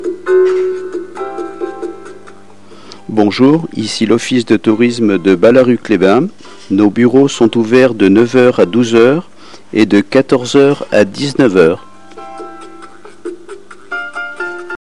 Si le récepteur de la balise reconnaît la demande d’information alors un message sonore est diffusé par le haut-parleur de la balise.
(Message d’accueil de l’Office de Tourisme de Balaruc les Bains)